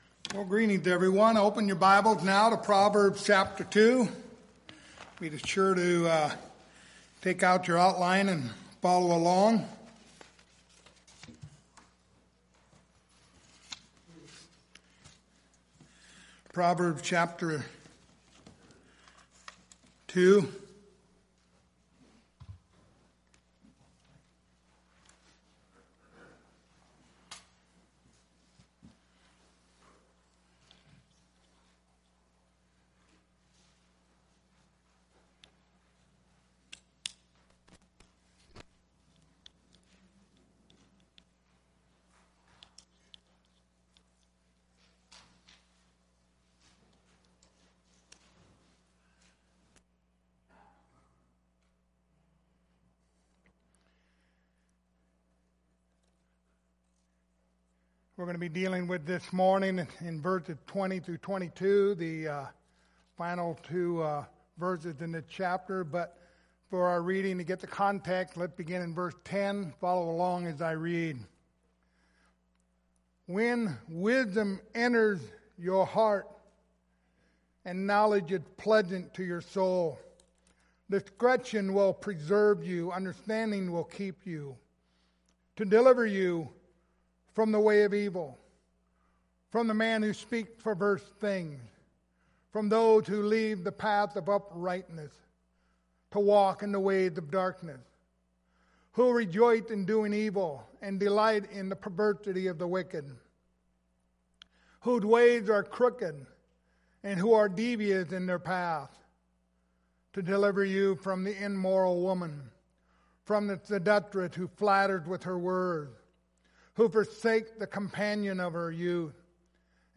Proverbs 2:20-22 Service Type: Sunday Morning Topics